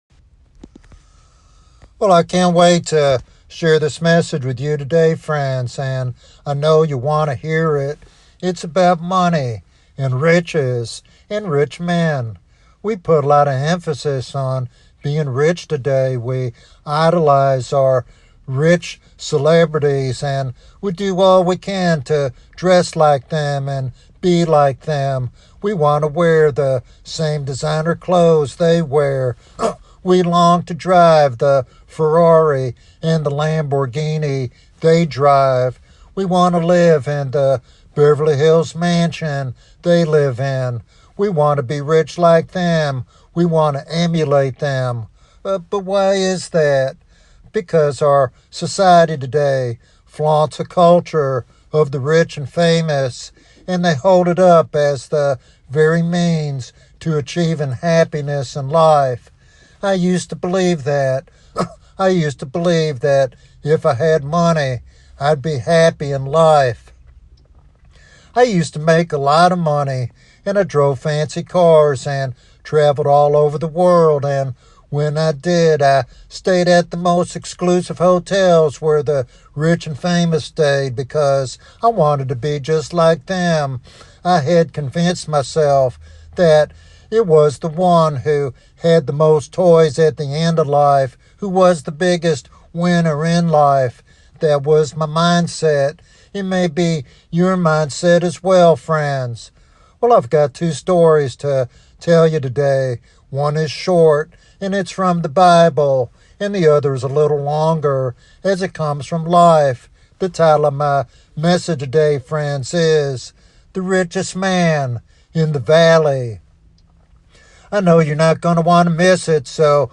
This sermon challenges cultural values and invites all to come to Christ as their Savior and Lord.